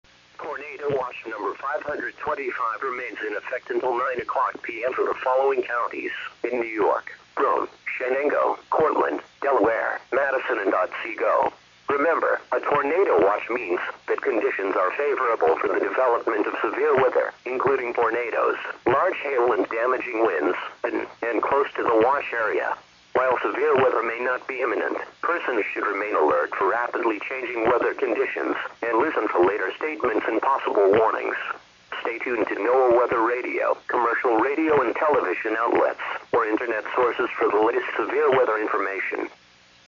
Tornado Watch